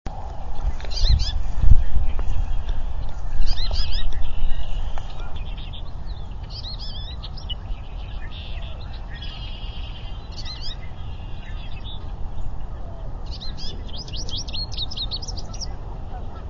Goldfinch
Goldfinch male and female talking back and forth.
goldfinch_pair_talking_862.wav